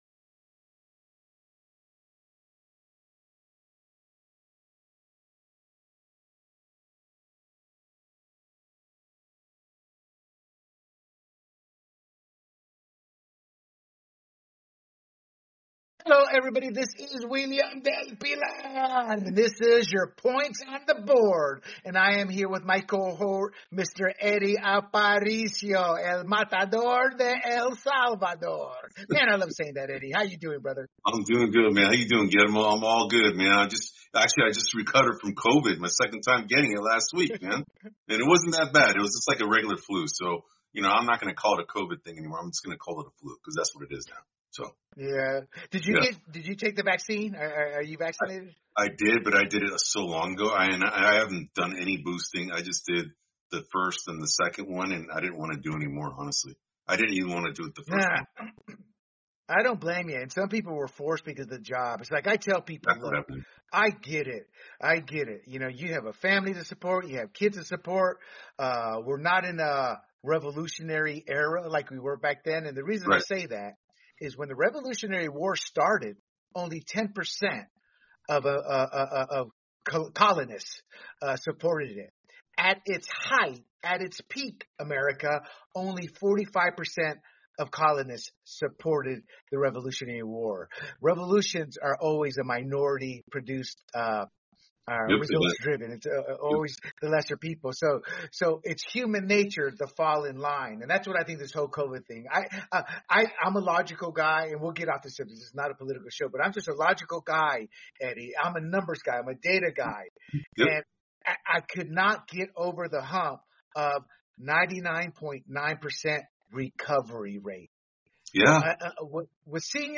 In this episode of Points on the board, the two amigos spout off on a wide range of topics, including the NFL Playoffs, Draymond Green, the NBA trade deadline.